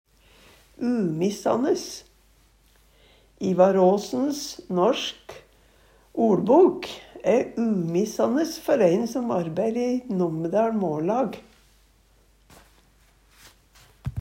Høyr på uttala Ordklasse: Adverb Attende til søk